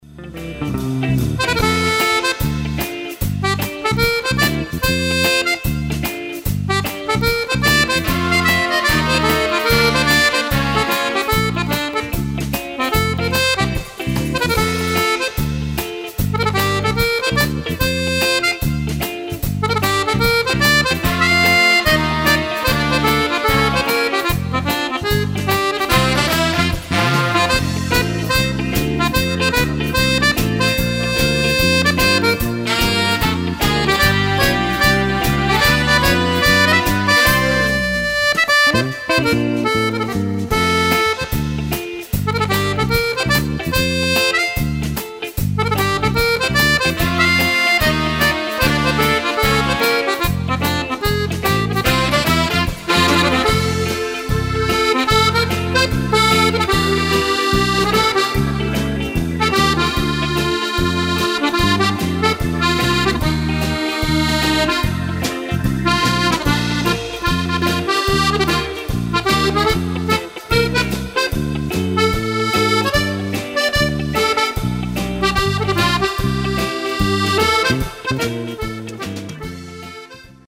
Moderato swing
Fisarmonica